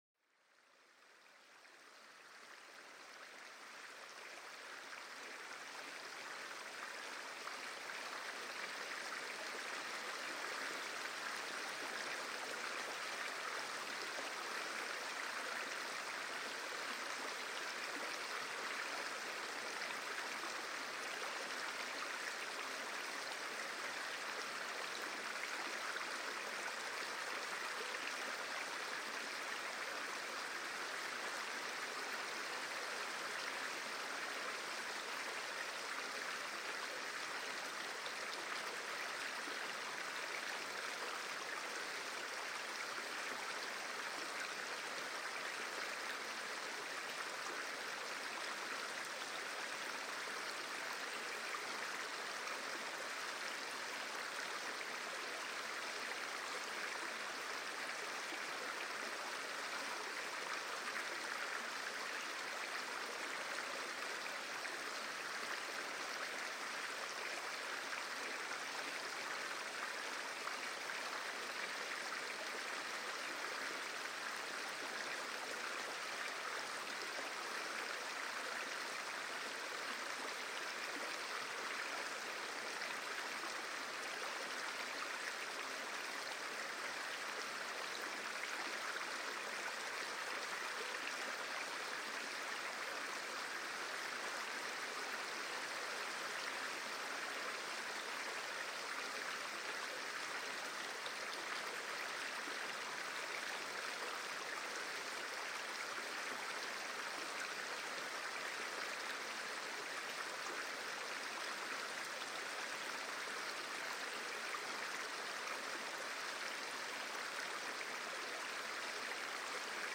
Relaxation avec le Son de l'Eau qui Coule: Apaisement et Sérénité
Écoutez le doux murmure de l'eau qui coule dans la rivière, une invitation à la détente et à la méditation. Plongez-vous dans cette ambiance naturelle pour réduire le stress et favoriser un sommeil réparateur.